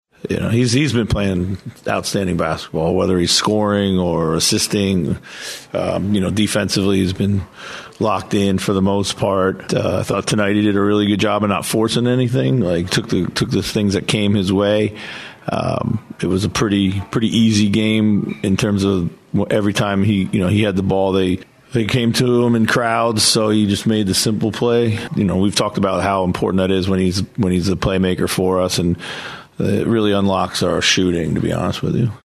Timberwolves head coach Chris Finch on the play of Julius Randle.